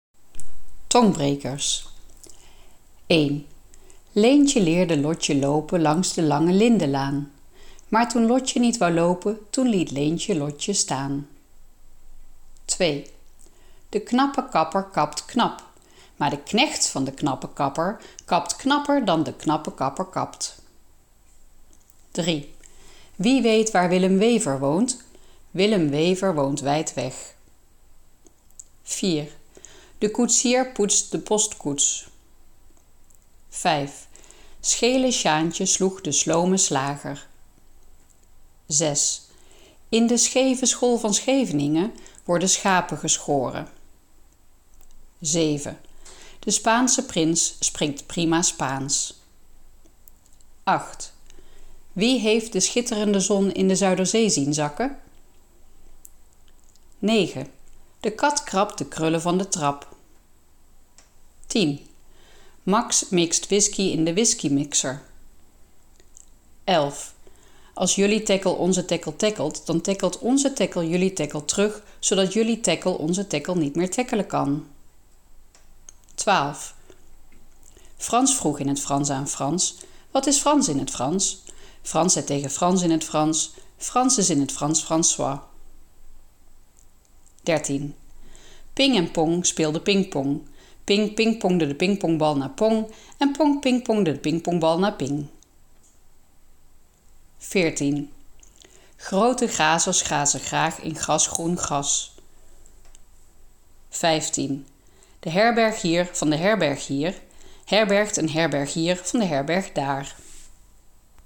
Tongbrekers_TZ.mp3